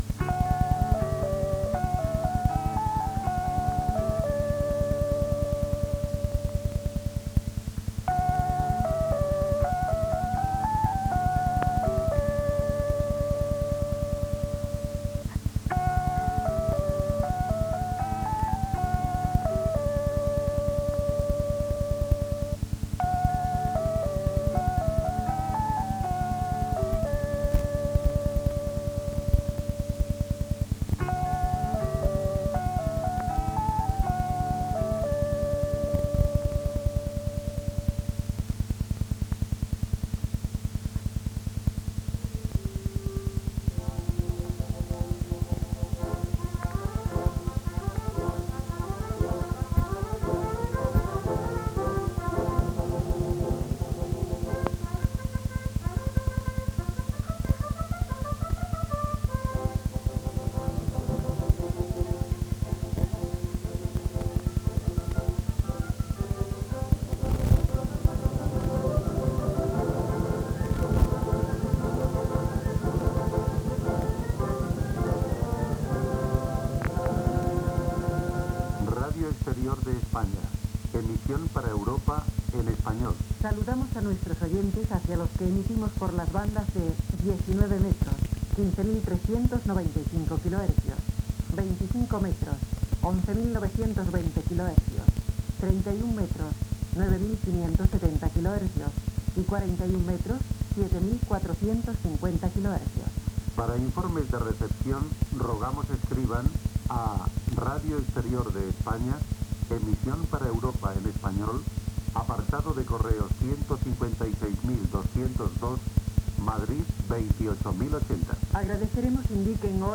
Sintonia, inici de l'emissió
Gènere radiofònic Informatiu
Enregistrament fet des de l'Esquerra de l'Eixample de Barcelona.